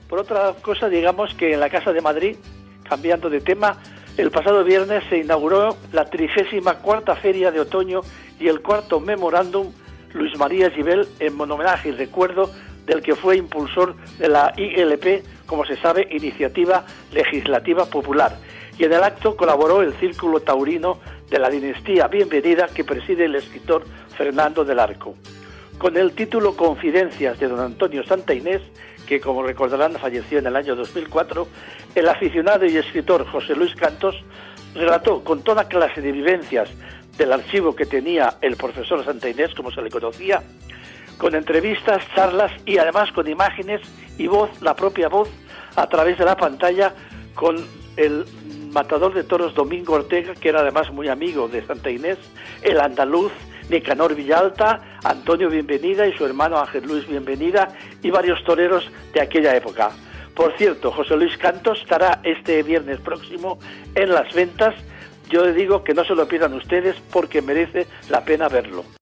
Presentador/a